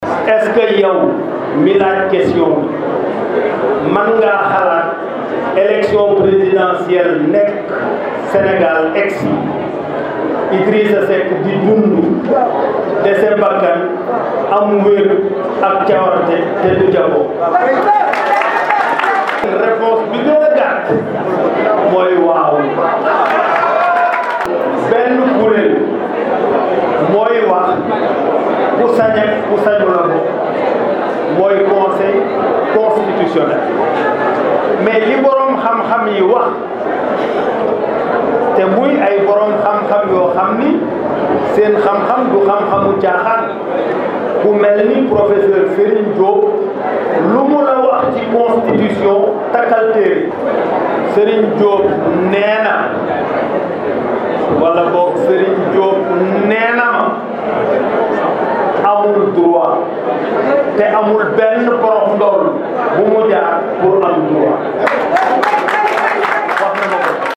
Face à la presse ce vendredi au cyber campus de Thiès Idrissa Seck qui juge que Ousmane Sonko est “violent” est revenu sur sa visite au domicile du leader du Pastef .Mais on retiendra que le leader de Rewmi s’est prononcé sur une probable candidature de Macky Sall avant de confirmer ‘sa” candidature pour la présidentielle de février 2024